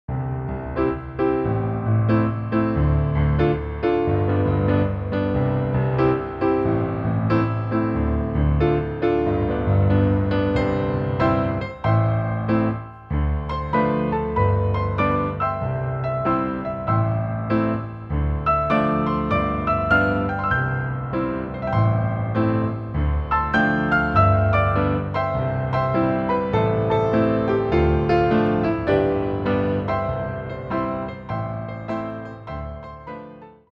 Piano Microphone Test
For the inside piano takes, I placed the mic's as shown in the pictures at about the middle distance of the length of the stings.
All microphones were connected via Monster Cable Performer 500 mic cables through two Digimax for 14 similar microphone preamps connected via light pipe to Alesis HD24.
Inside Mic Evaluation
It seems the SCX25 has a touch more presence than the CX112. Take into consideration, when you are doing close miking inside the piano, proximity effect lessens the need for extreme low end.